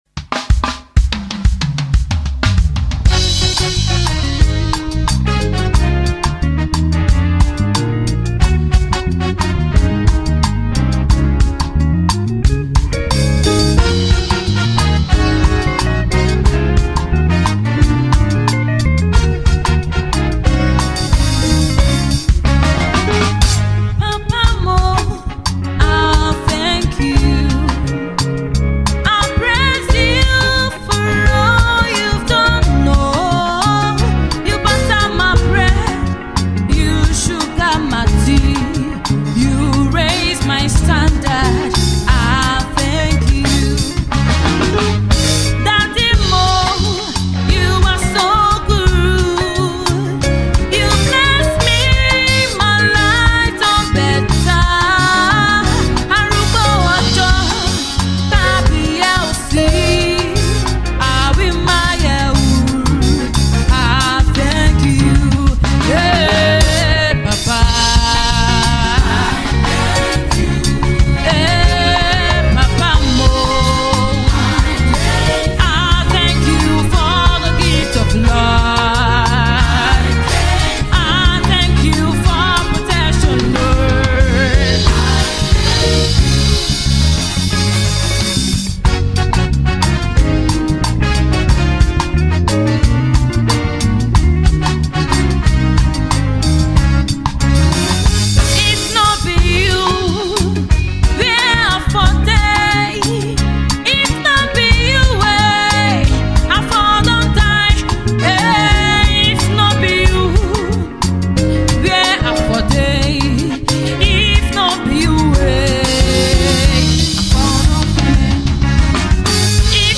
gospel group
soulful worship